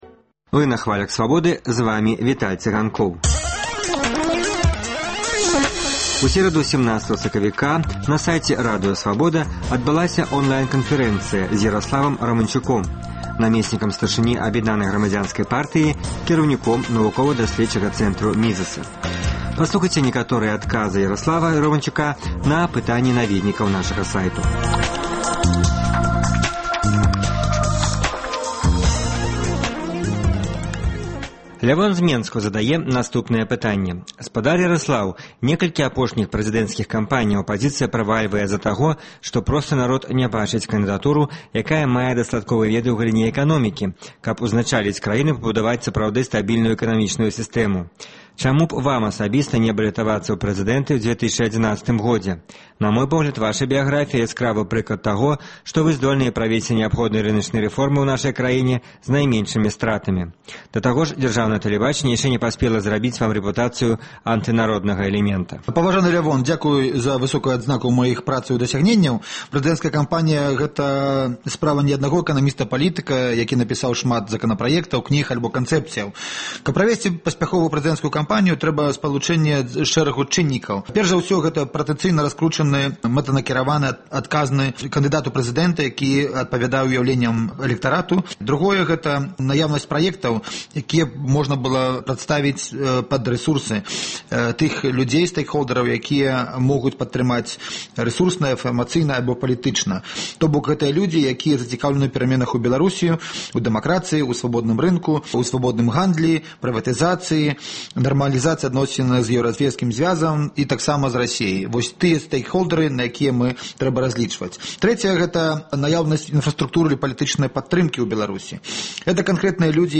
Онлайн- канфэрэнцыя